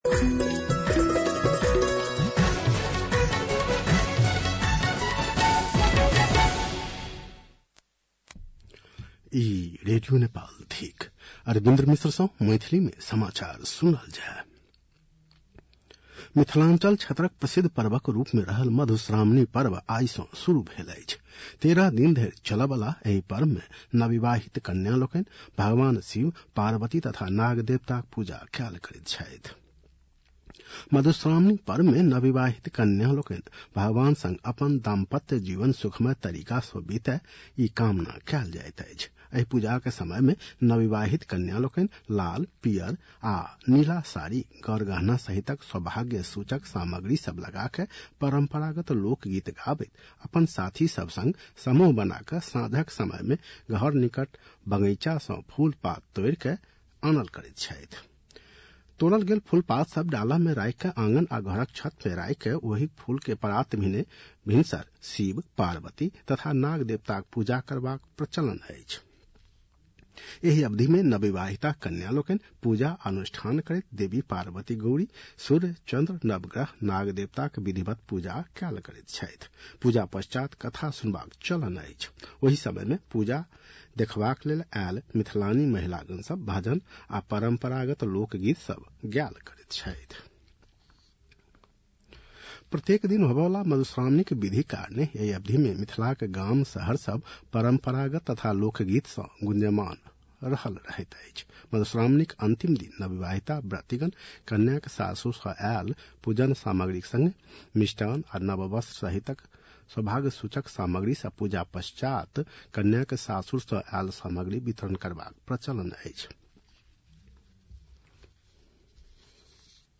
मैथिली भाषामा समाचार : ३१ असार , २०८२